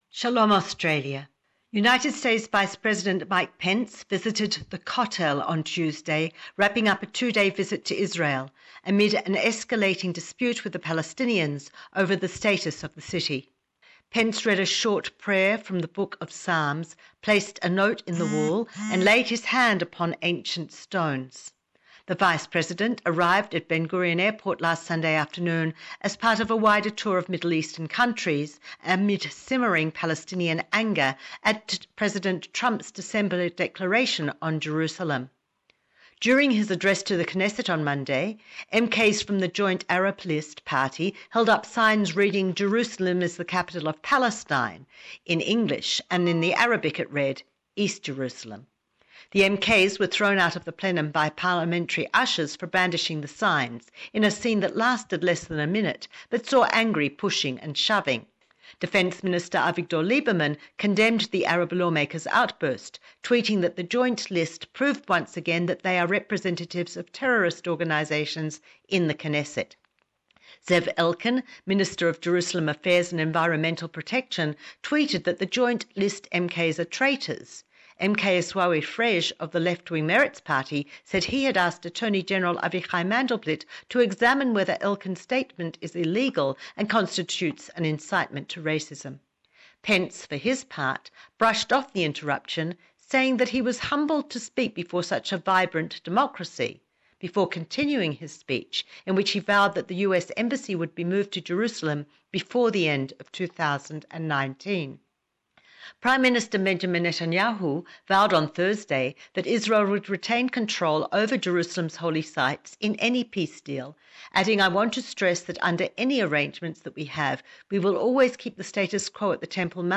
Don't miss our unique Jerusalem report